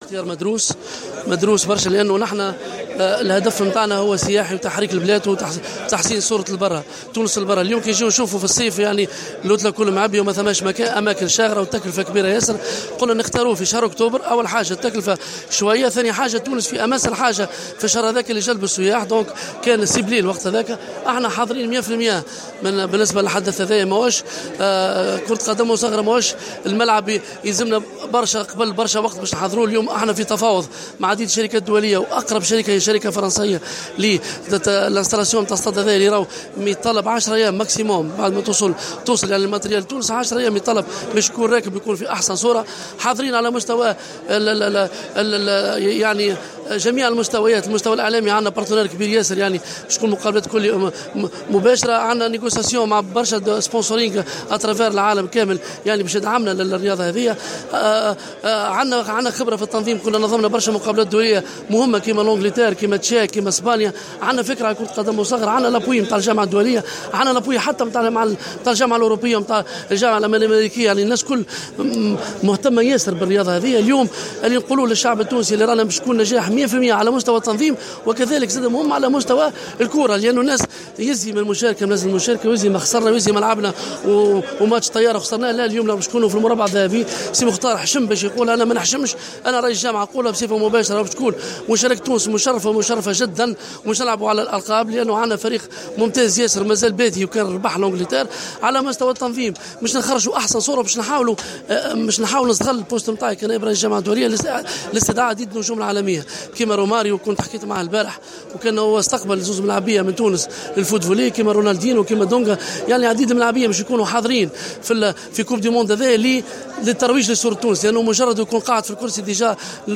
عقدت الجامعة التونسية لكرة القدم المصغرة اليوم ندوة صحفية للحديث حول تنظيم تونس لكأس العالم لكرة القدم المصغرة 2017.